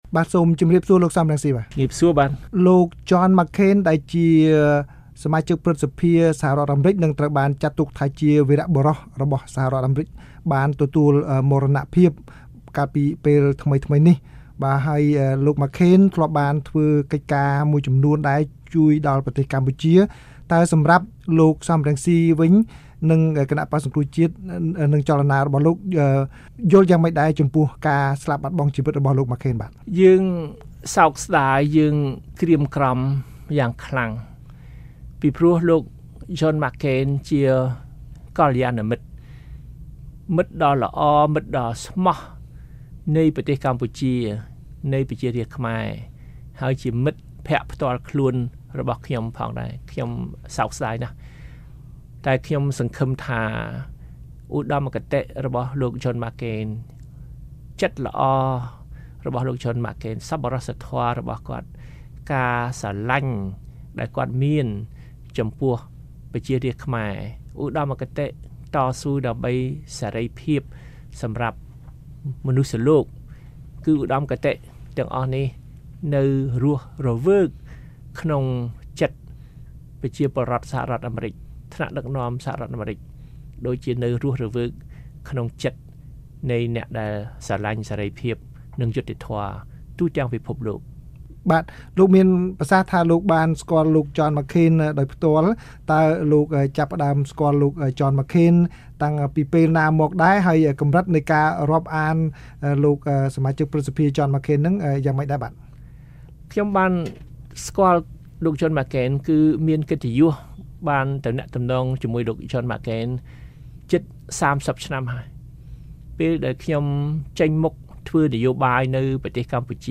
បទសម្ភាសន៍ VOA៖ លោកសម រង្ស៊ី រំលឹកពីវីរភាពនិងការងារជាមួយនឹងលោកចន ម៉ាក់ខេន